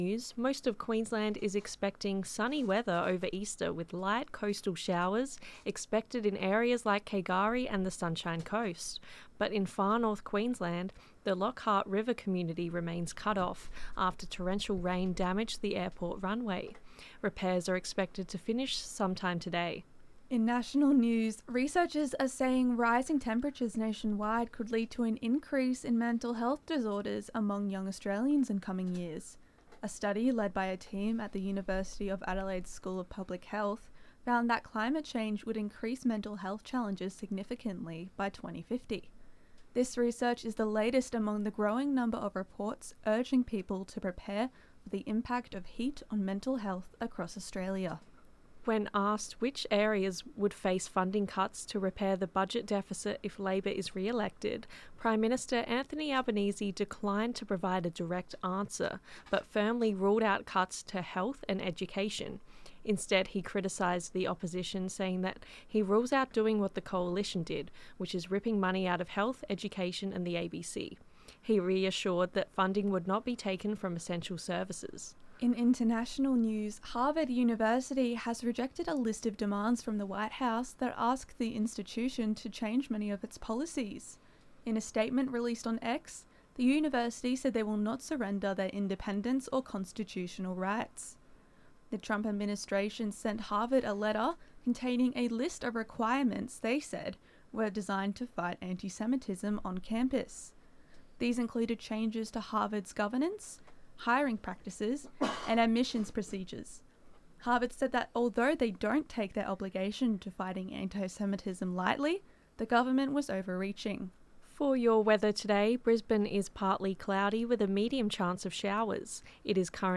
Drought (Pixabay/Pexels under CC BY-ND 2.0) Zedlines Bulletin 11AM ZEDLINES 15.4.25.mp3 (2.4 MB)